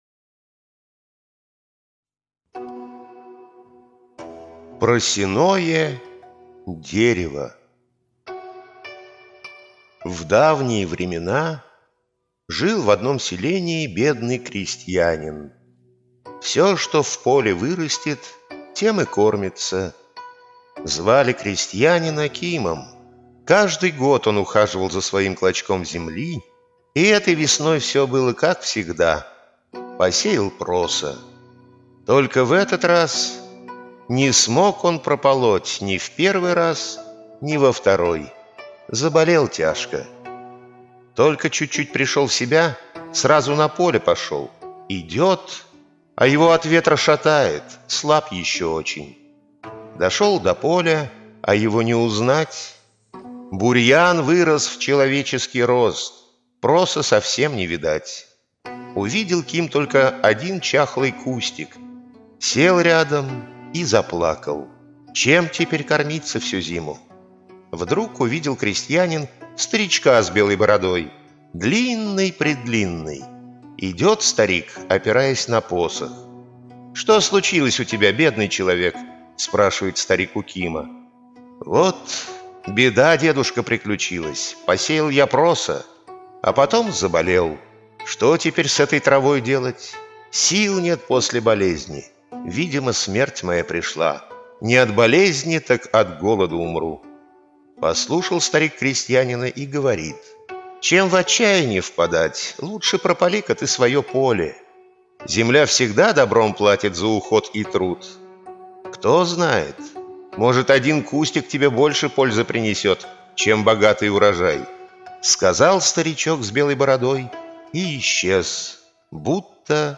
Просяное дерево - корейская аудиосказка - слушать онлайн